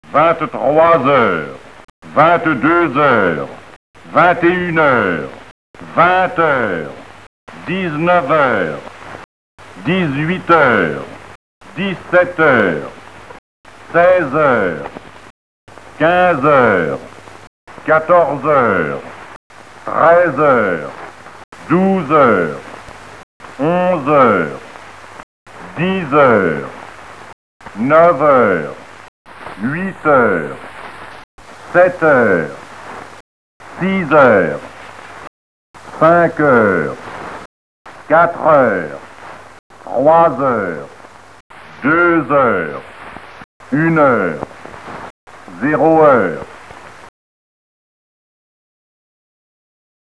Accessible en composant un numéro dédié, elle énonce l’heure avec une précision à la seconde près, rythmée par un signal sonore.